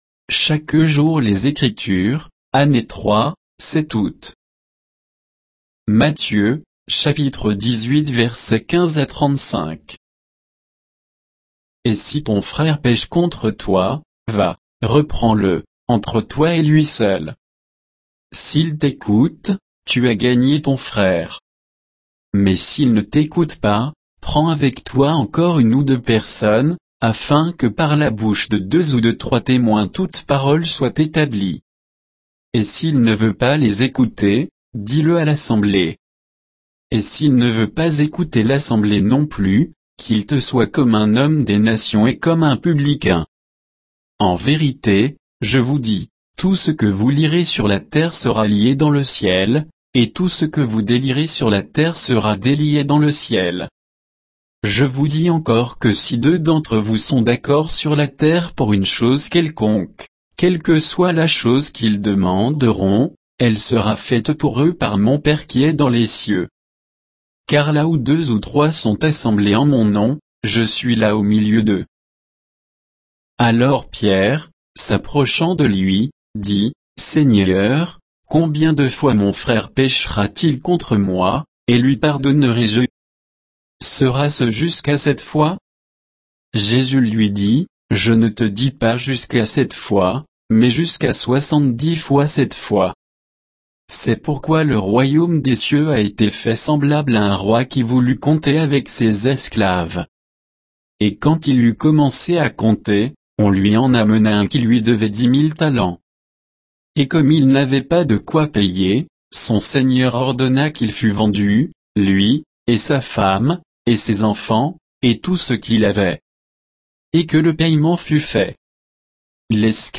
Méditation quoditienne de Chaque jour les Écritures sur Matthieu 18